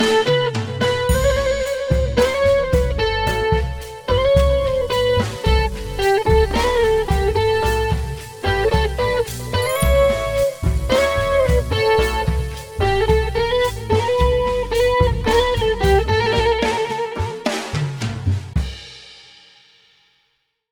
Waves Audio: Waves Creative Tip 💡 Hard Pitch Correction on… Guitar?!?
waves-tune-with-guitar-audio-example.mp3